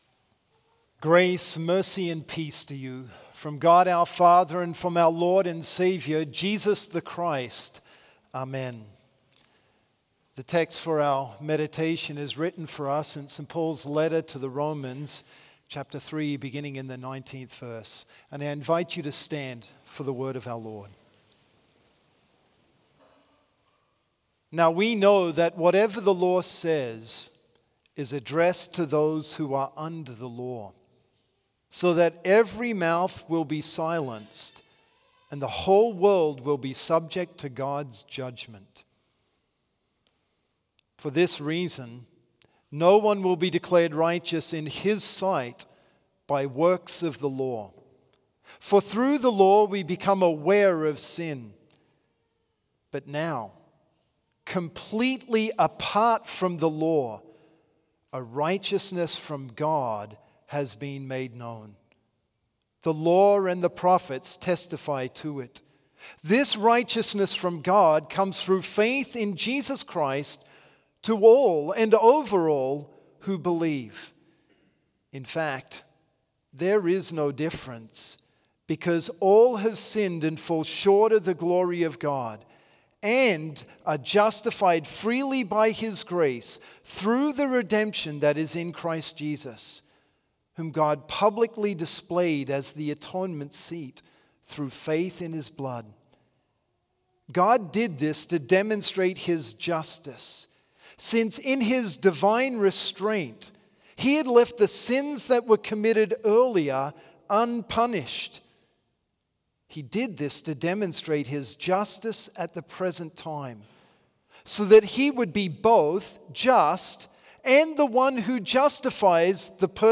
Complete service audio for Reformation Vespers - October 30, 2025